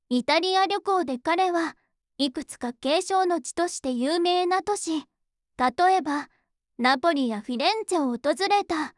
voicevox-voice-corpus
voicevox-voice-corpus / ita-corpus /もち子さん_怒り /EMOTION100_011.wav